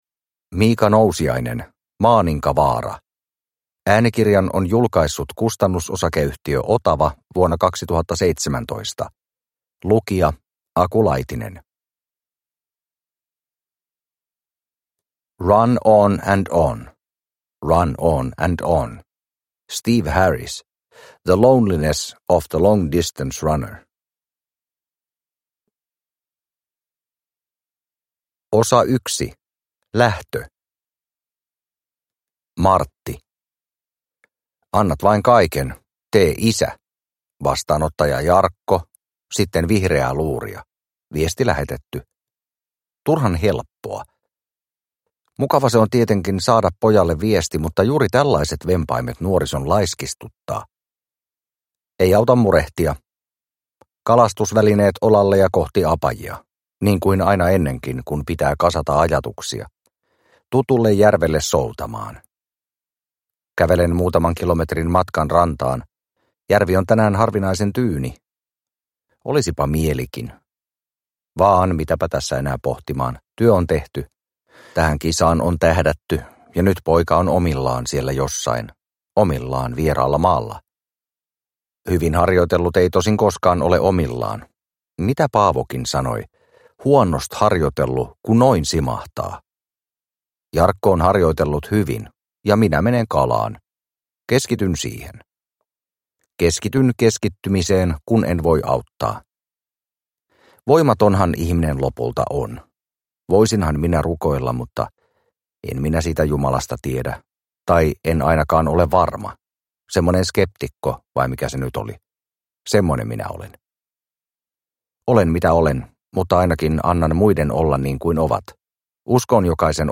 Maaninkavaara – Ljudbok – Laddas ner